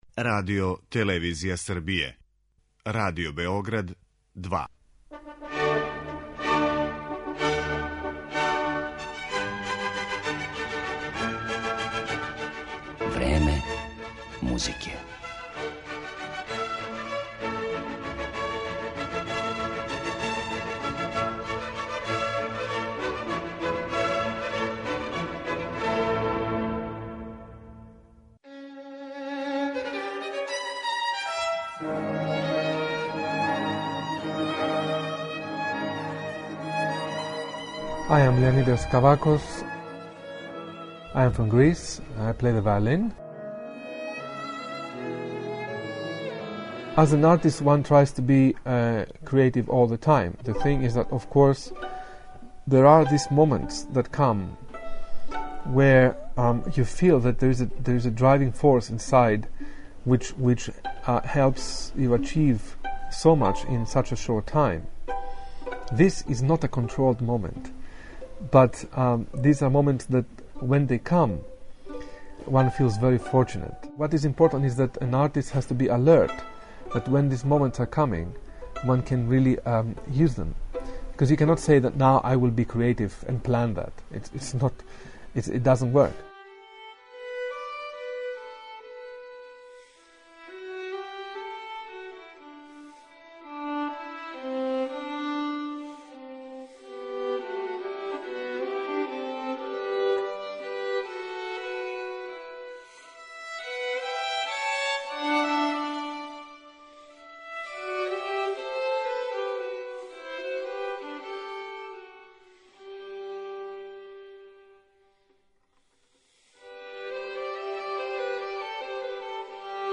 Његов портрет ћемо насликати музиком Бетовена, Брамса, Сибелијуса, Исаија и других композитора, чија је дела (током каријере дуге преко тридесет година) снимио и представио концертној публици широм света. У емисији ћемо емитовати и део интервјуа забележеног 2018. године у Котору где је славни грчки виолиниста одржао концерт са пијанисткињом Јуђом Ванг.